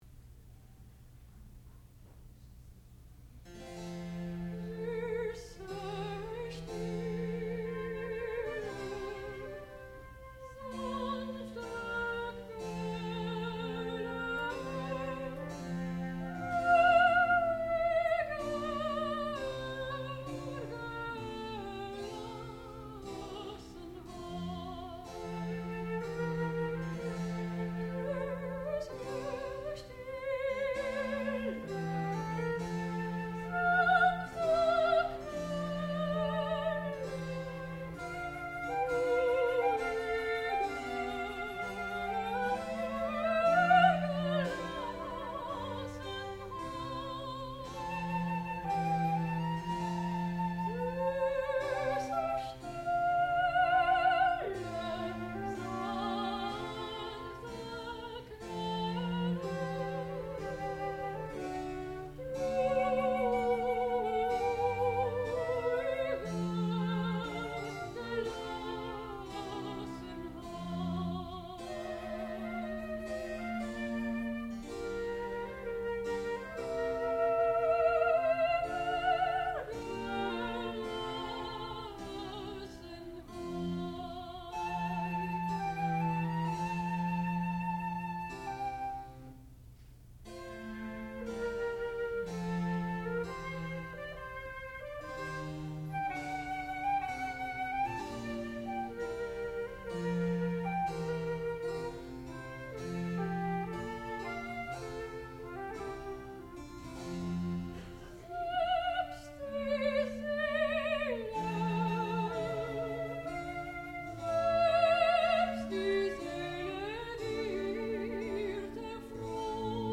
sound recording-musical
classical music
violoncello
harpsichord
soprano